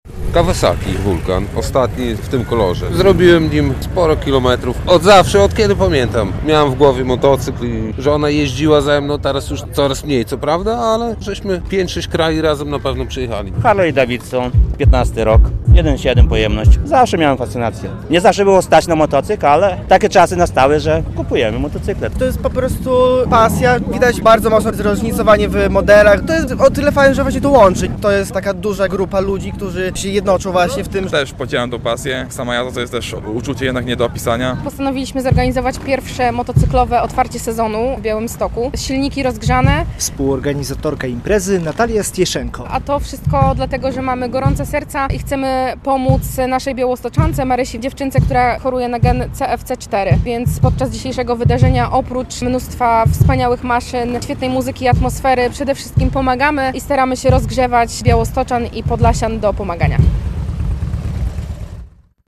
Jest głośno i kolorowo, a wszystko w szczytnym celu.
Na placu przed Teatrem Dramatycznym można podziwiać zarówno starsze jak i nowsze modele motocykli.